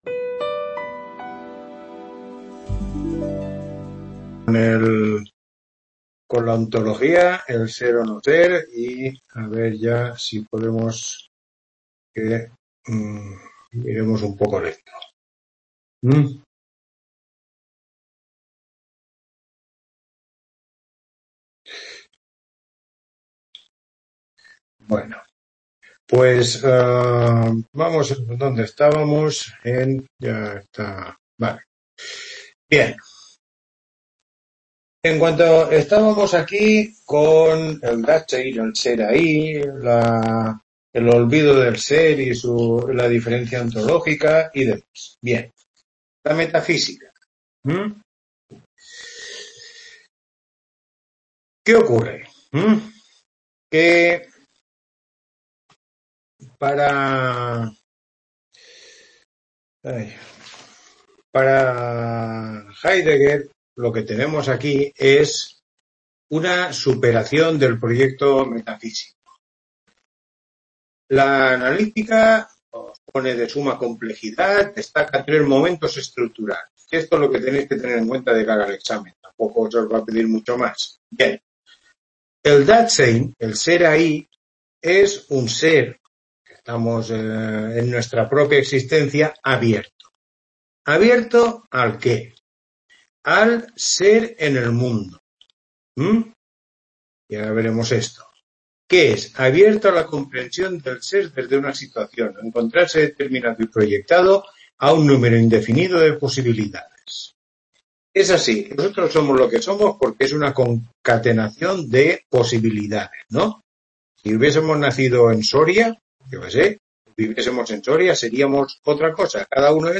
Tutoría 11